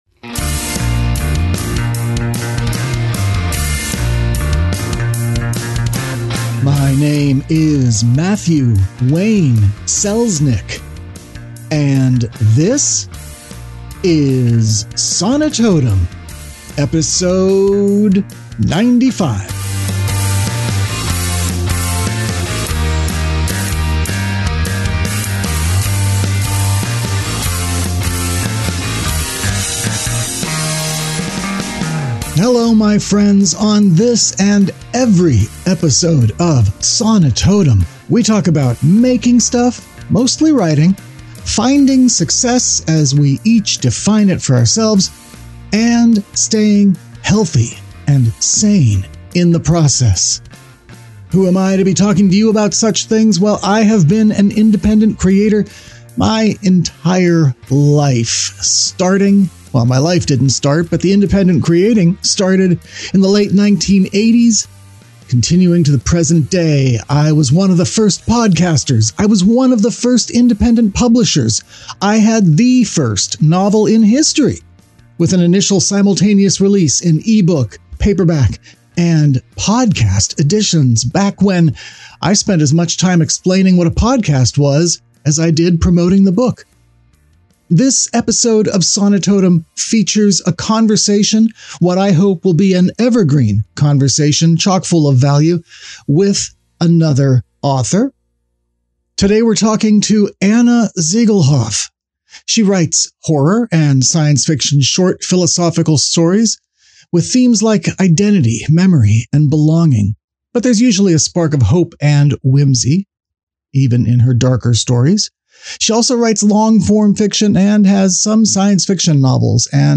Sonitotum 95: A Conversation w